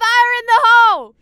Added all voice lines in folders into the game folder
Maddie Im firing.wav